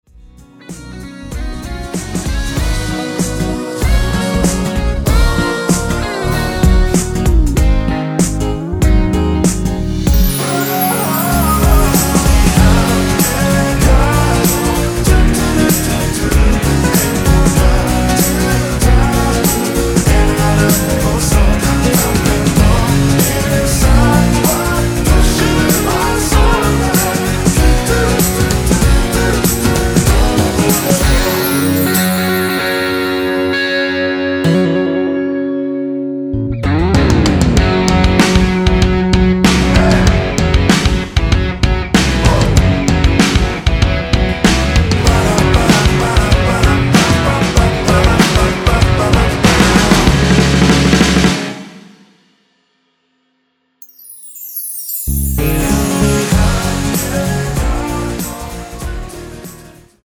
원키에서(-2)내린 코러스 포함된 MR입니다.(미리듣기 확인)
Gb
앞부분30초, 뒷부분30초씩 편집해서 올려 드리고 있습니다.
중간에 음이 끈어지고 다시 나오는 이유는